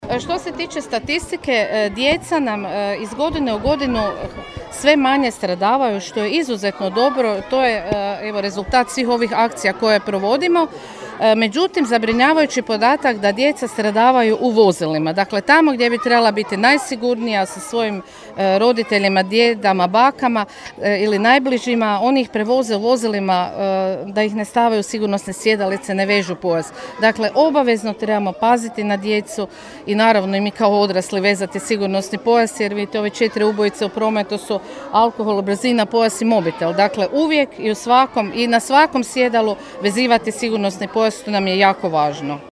U sklopu nacionalne akcije “Poštujte naše znakove” , na Trgu Republike u Čakovcu održana je akcija “Sigurno i vješto u prometu”.